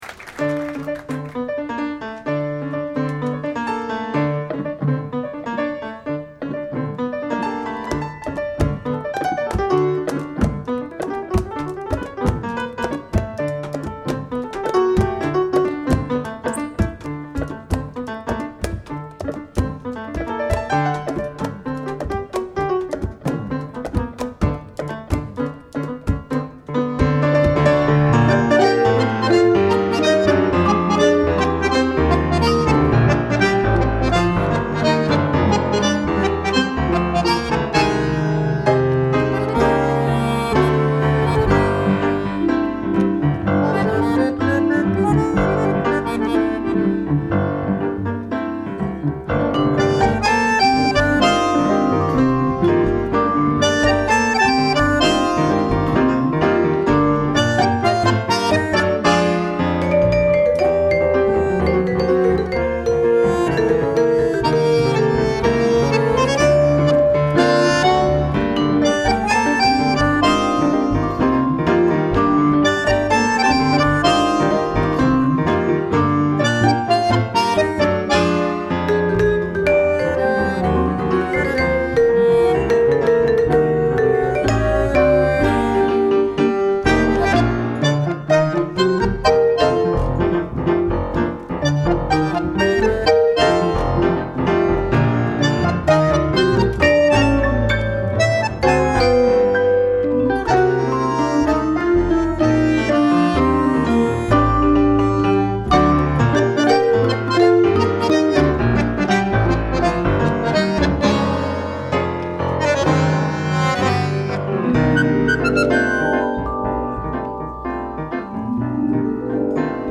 Live at Jazz Standard